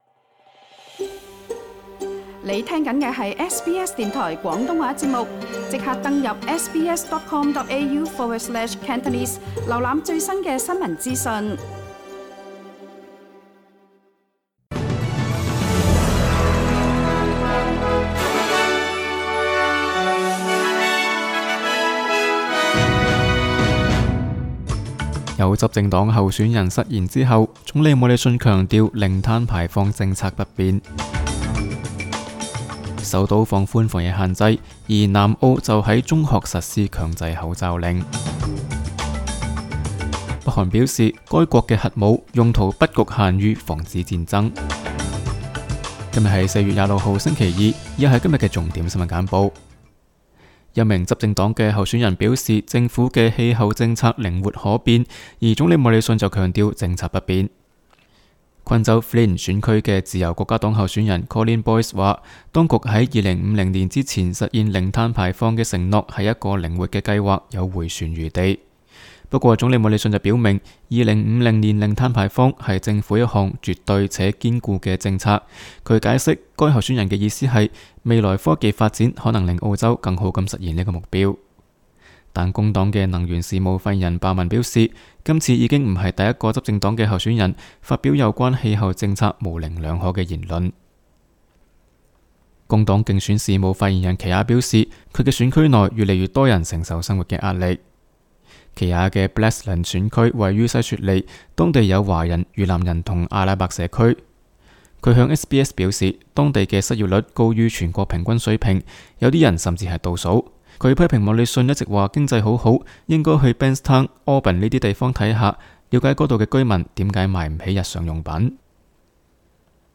SBS 新闻简报（4月26日）